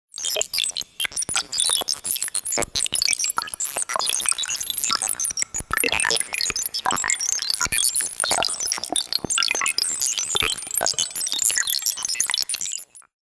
zvuki-inoplanetjan_005.mp3